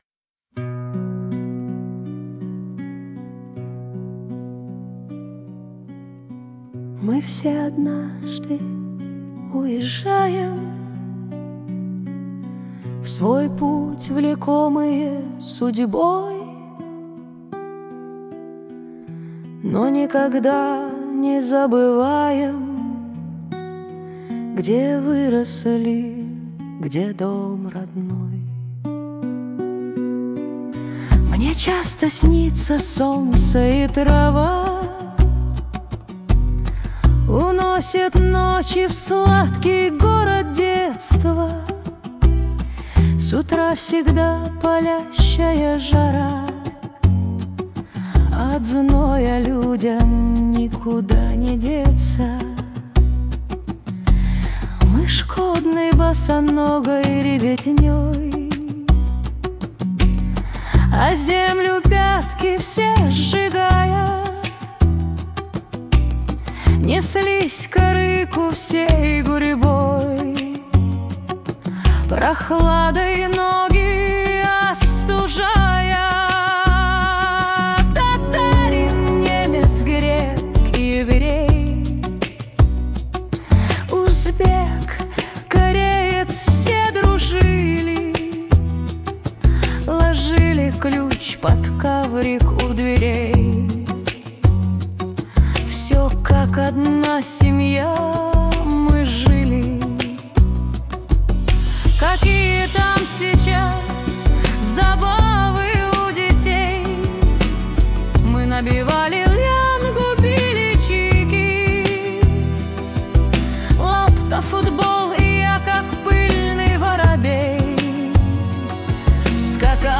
но по-моему, исполнение очень похоже на ИИ под Севару Назархан.